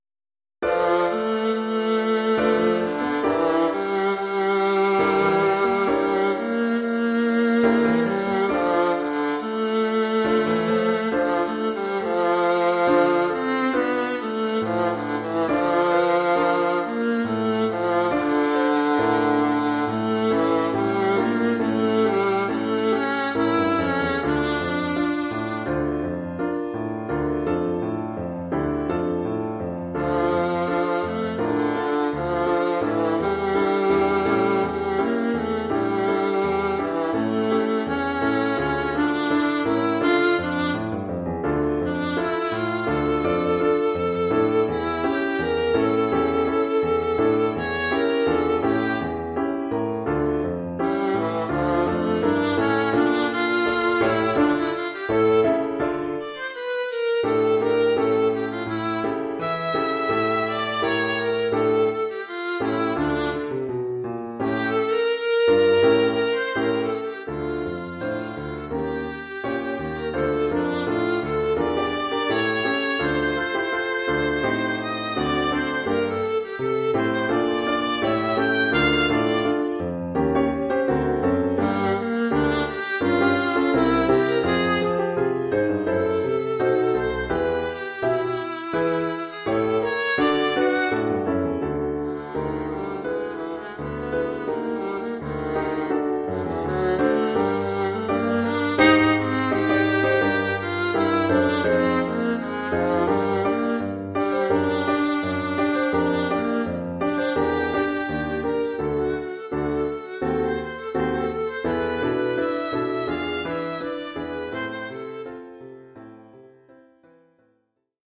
1 titre, alto et piano : conducteur et partie d’alto
Oeuvre pour alto et piano..